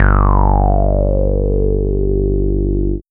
CHAOS BASS 2.wav